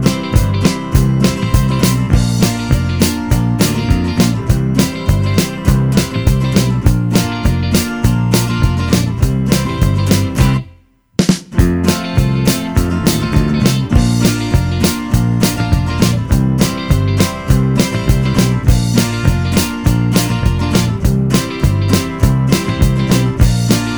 No Backing Vocals Rock 'n' Roll 2:20 Buy £1.50